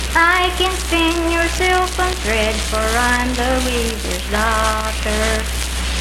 Unaccompanied vocal music
Voice (sung)
Spencer (W. Va.), Roane County (W. Va.)